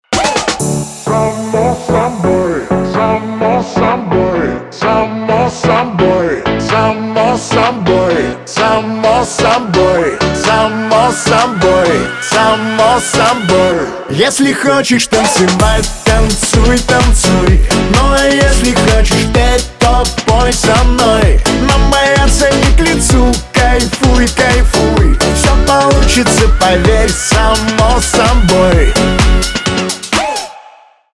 поп
танцевальные
позитивные
пианино , нарастающие